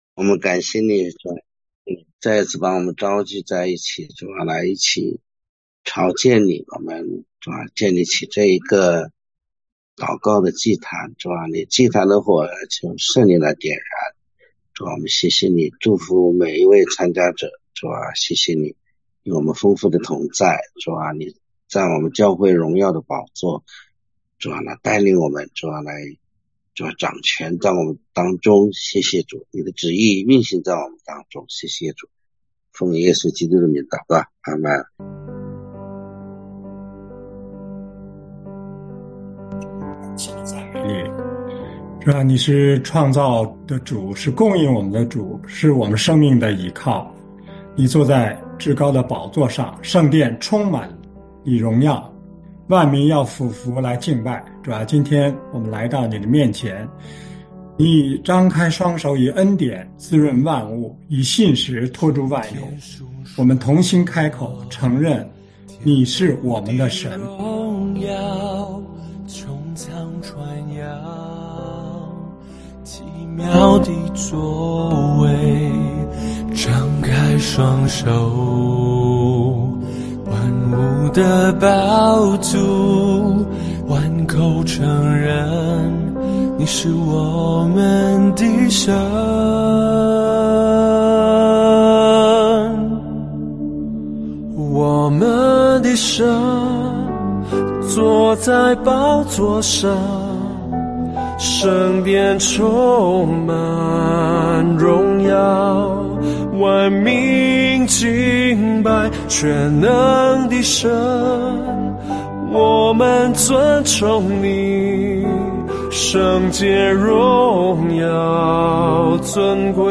晨祷